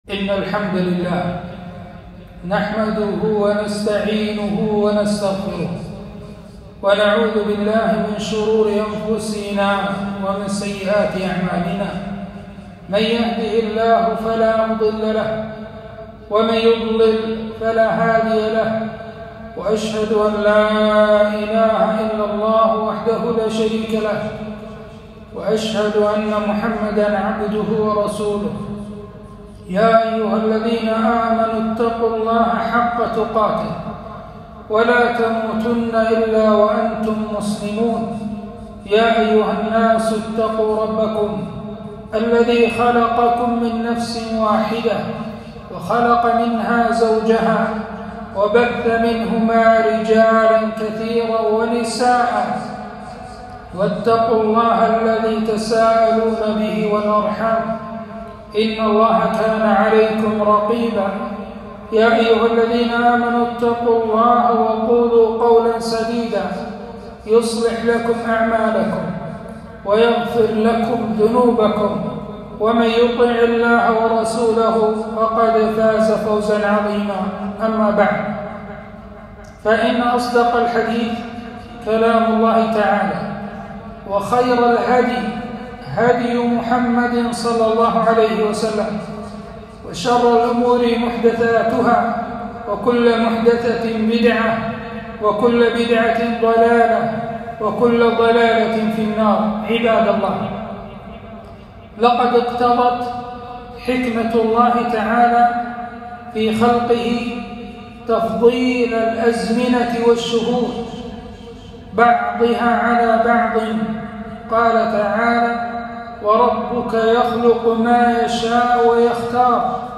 خطبة - محرم وعاشوراء أحكام وآداب 4-1-1443